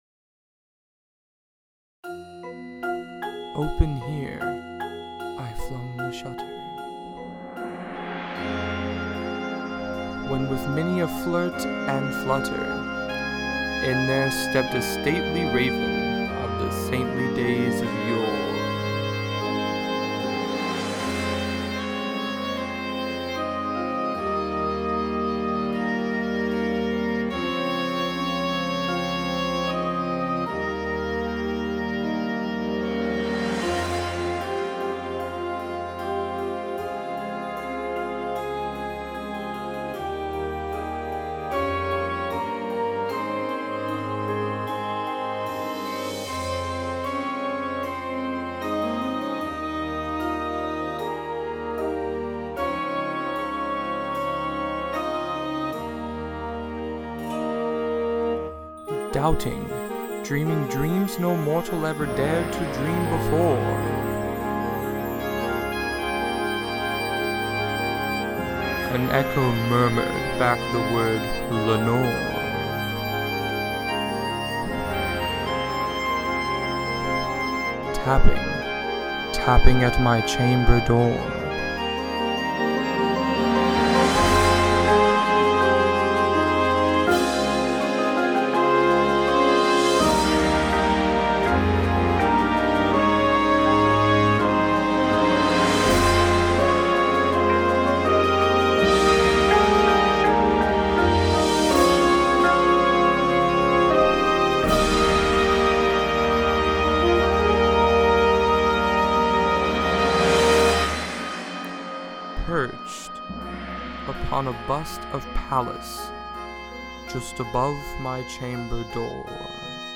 • Tuba
• Snare Drum